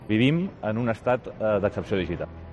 Declaraciones Jordi Puigneró